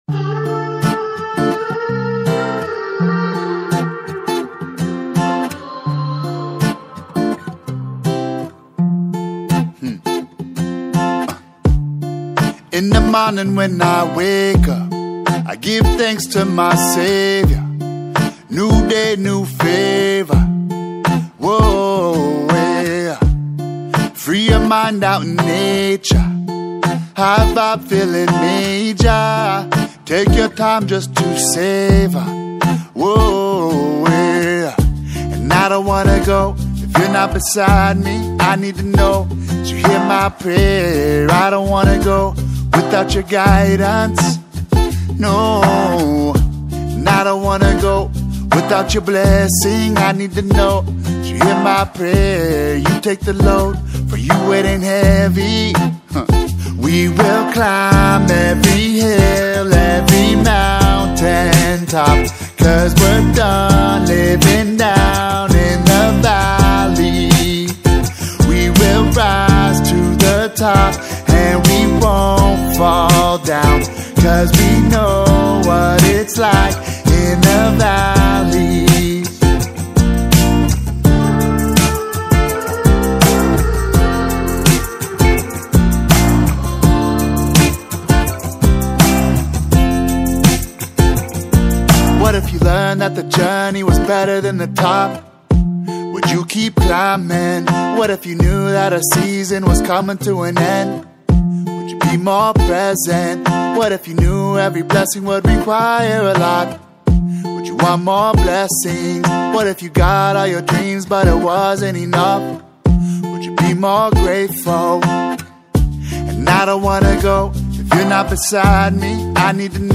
Genre: Gospel/Christian